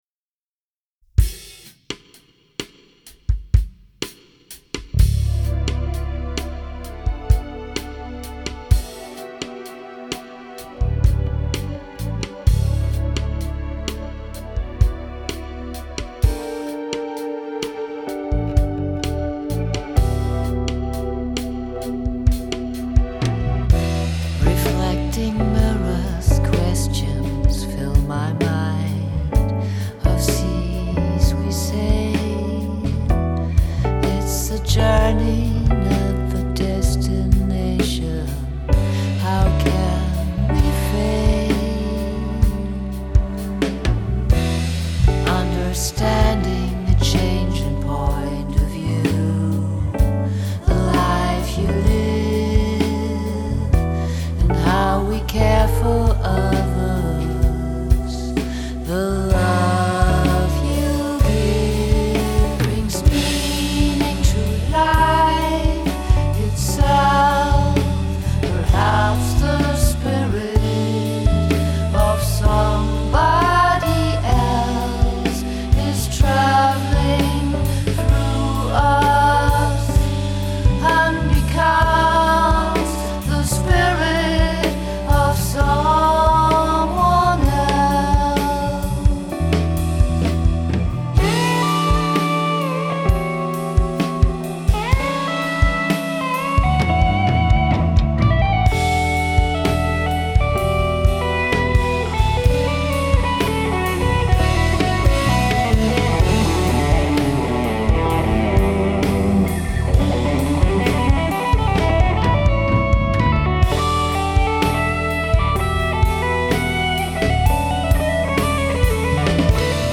Den Mix habe ich über mein kleines Homestudio gemacht.
Komposition/Gitarre
Schlagzeug und Gesang
Bass